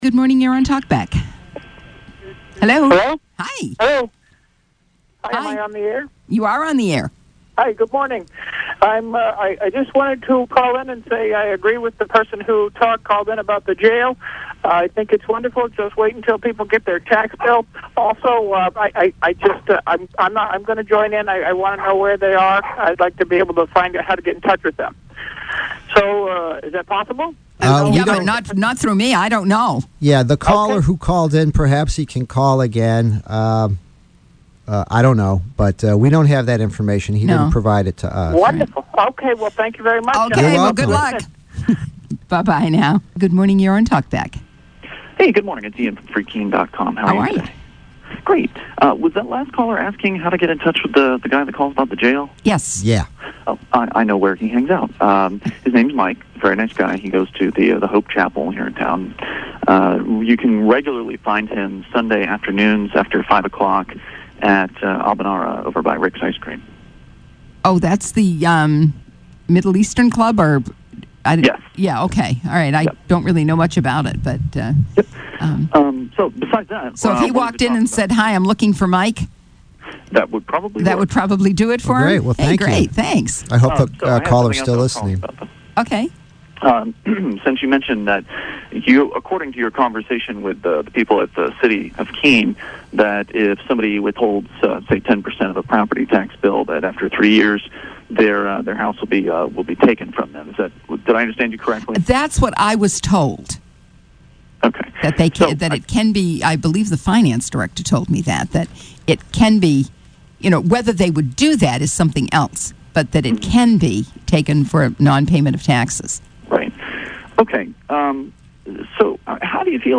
Liberty-minded people called “Talkback” on WKBK last week to discuss stealing property, agreements, providing unwanted services, arbitrary extractions, and a tax revolt. We also hear from an offended caller, who calls liberty activists a “gang” – actually she calls them *my* gang, as though I’m in charge.